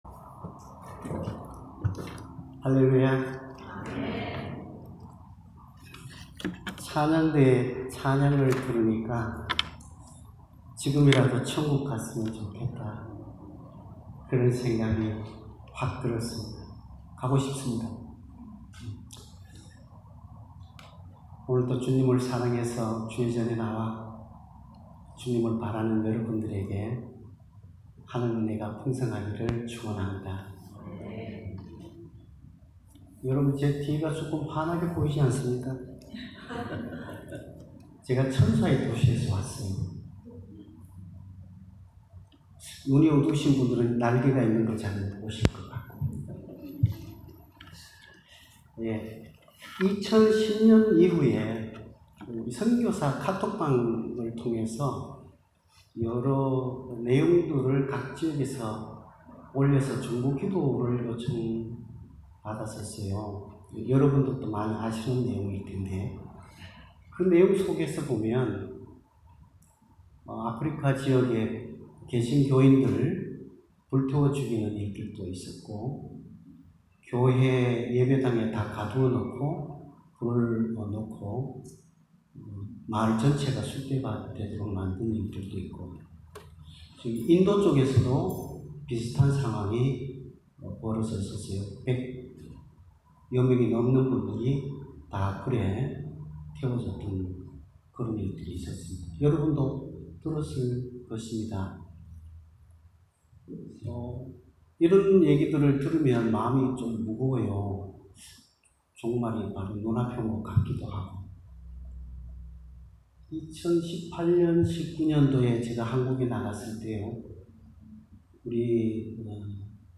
Bible Text: 엡3:7-12절 | 설교자